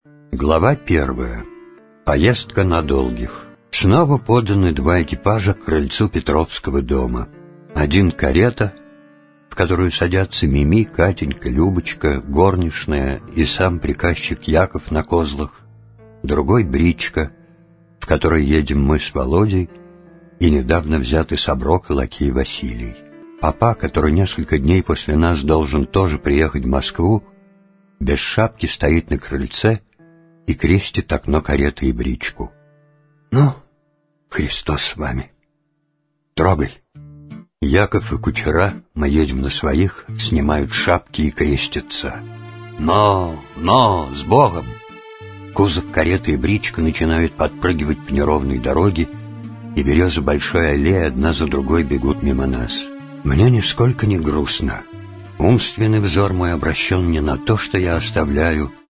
Аудиокнига Отрочество | Библиотека аудиокниг
Aудиокнига Отрочество Автор Лев Толстой